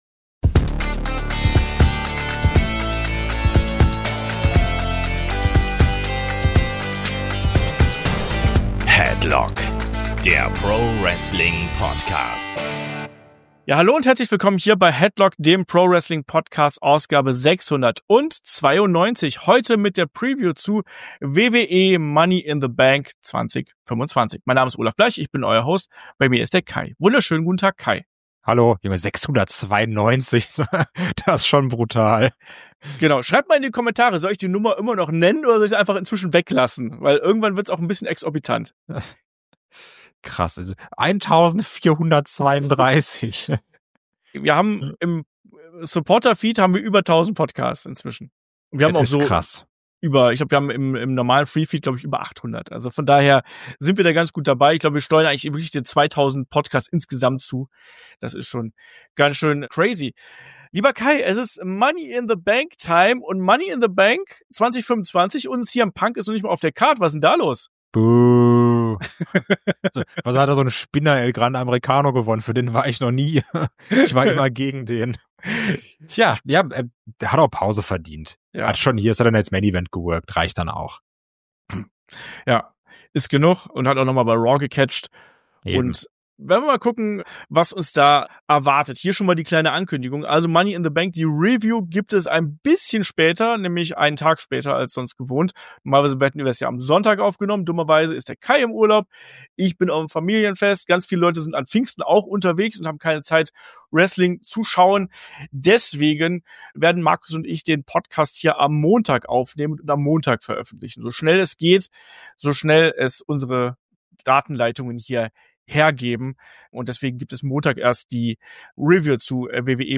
Headlock ist die Wrestling-Gesprächsrunde: Hier plaudert man nicht nur über das aktuelle WWE-Geschehen, sondern wirft auch einen Blick über den Tellerrand. Ganz egal, ob es um Karriere-Portraits alt gedienter Legenden geht oder um Konzeptfragen und Analysen - Headlock geht in die Tiefe, nimmt sich und Wrestling dabei aber nie ernster als es wirklich sein muss.